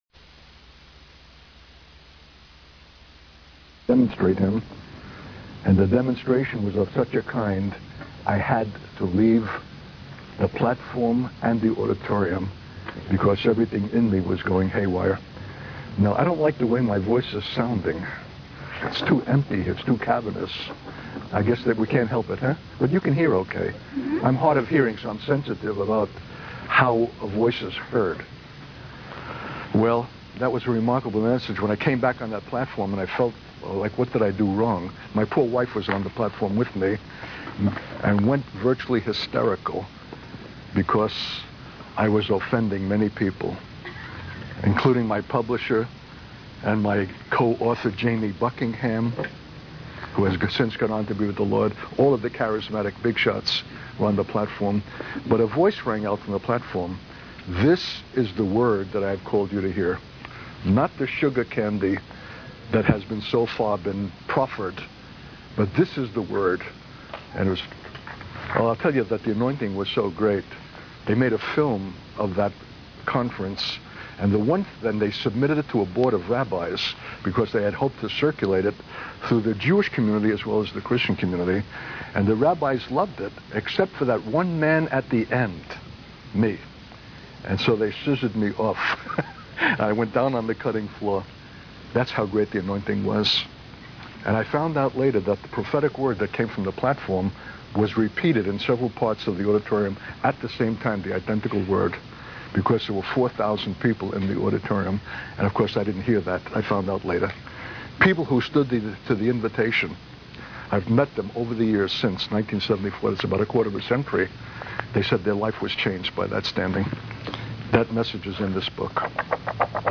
In this sermon, the speaker emphasizes the importance of knowing God as the revelation of Jesus as king and the beauty of the kingdom. He criticizes the superficiality of worship and the lack of true understanding and celebration of God's majesty. The speaker shares a personal experience of delivering a powerful message that offended many people, but was recognized as an anointed word by some.